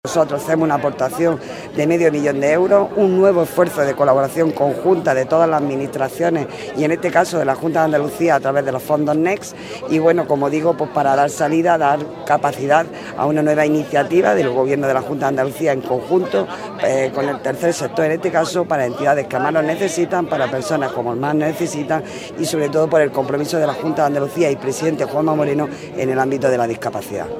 ARANZAZU-MARTIN-DELEGADA-GOBIERNO-JUNTA-PRIMERA-PIEDRA-CENTRO-VERDIBLANCA.mp3